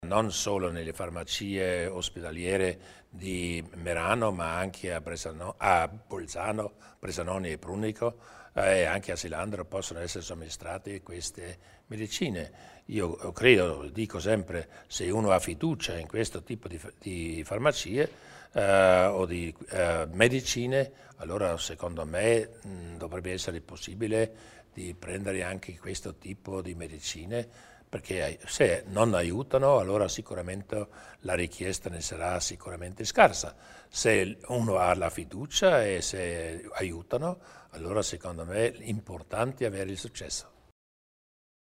Il Presidente Durnwalder illustra le novità in tema di medicina omeopatica